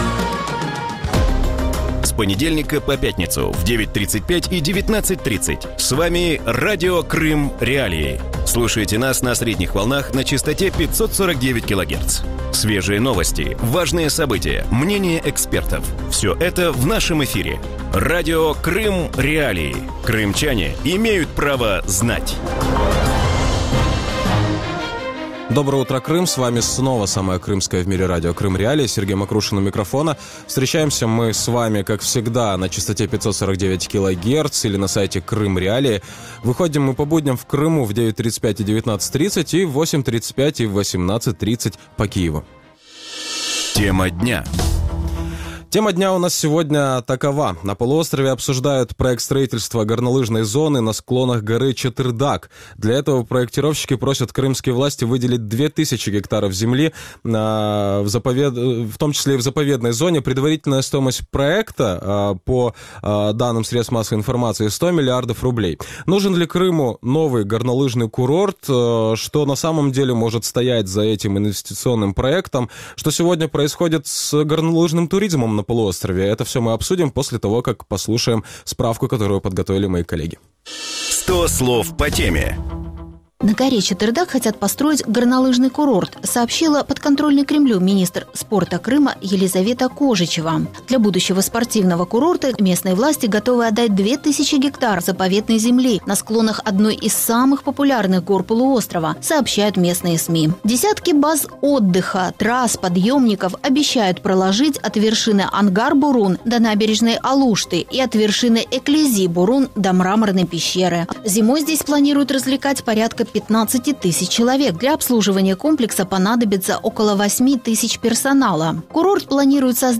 Вранці в ефірі Радіо Крим.Реалії говорять про гірськолижний туризм в Криму. На півострові обговорюють проект будівництва гірськолижної зони на схилах гори Чатир-Даг.